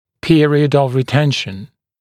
[‘pɪərɪəd əv rɪ’tenʃn][‘пиэриэд ов ри’тэншн]период ретенции